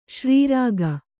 ♪ śrī rāga